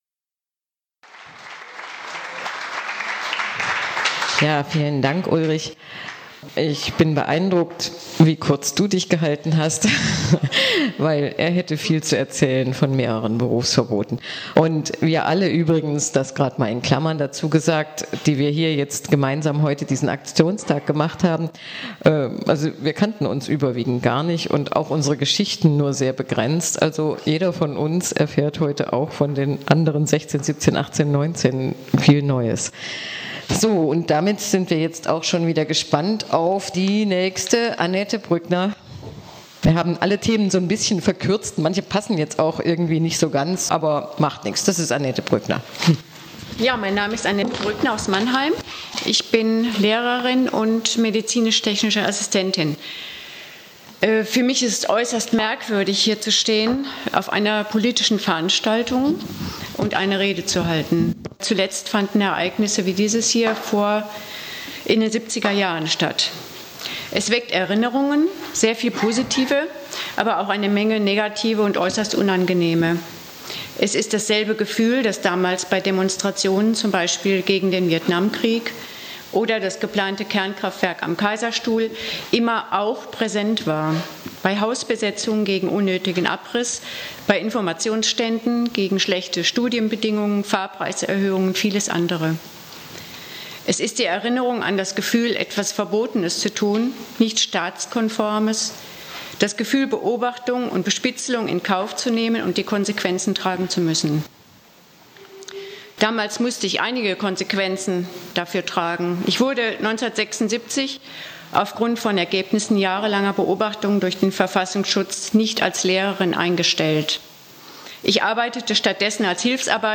Polithistorische Revue mit 17 Zeitzeuginnen und Zeitzeugen aus 8 Bundesländern,